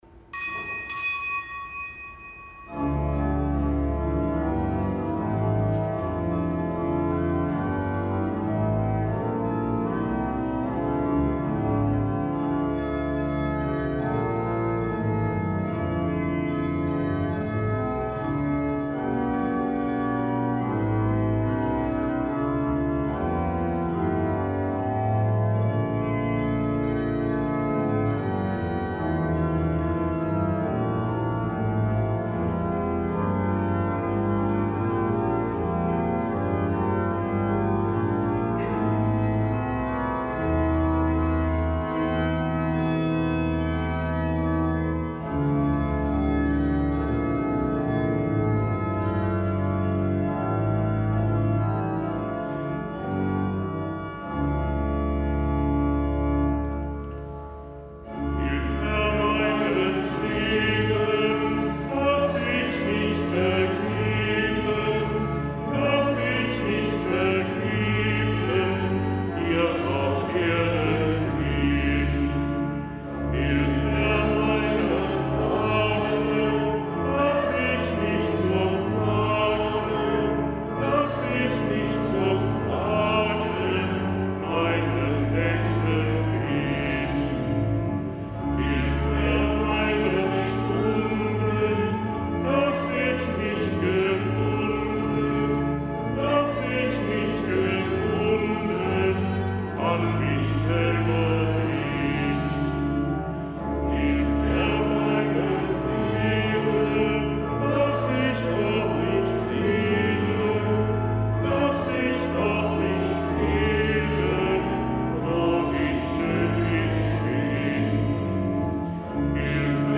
Kapitelsmesse aus dem Kölner Dom am Dienstag der achten Woche im Jahreskreis.